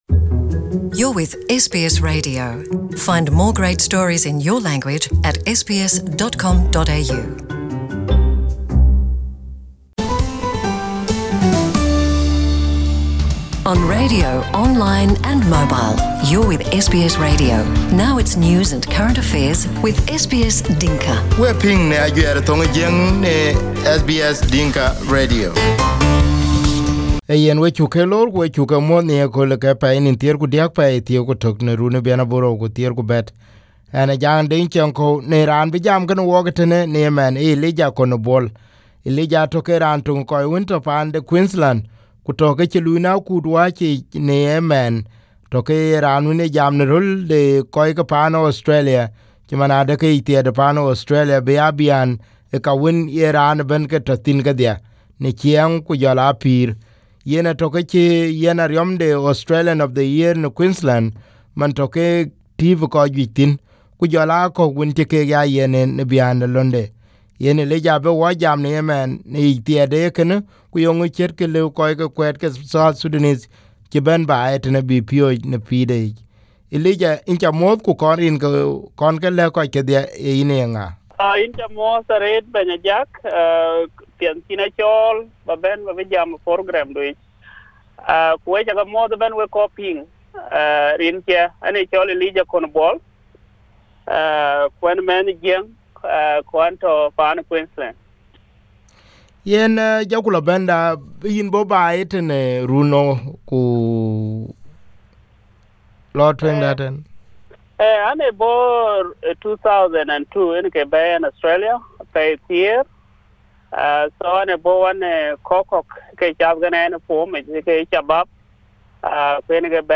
Here the part one of the interview.